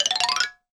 52-prc02-bala-fx2.wav